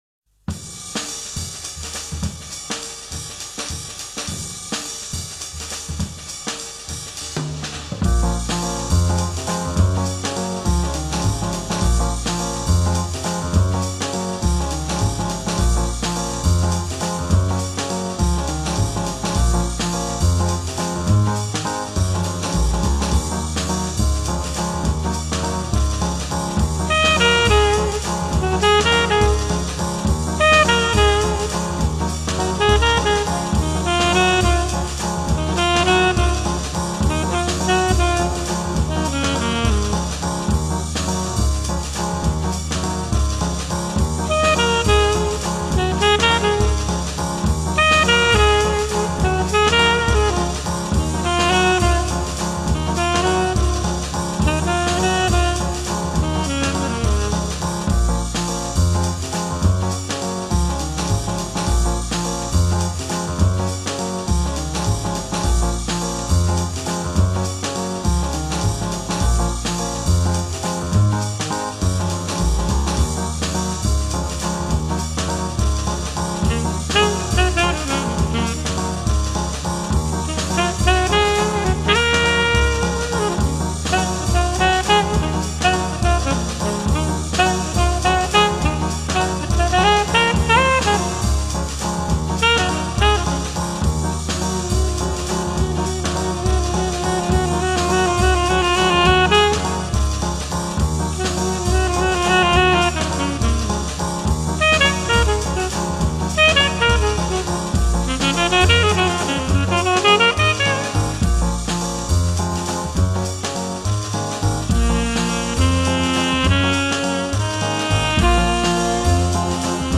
It’s quite the groove.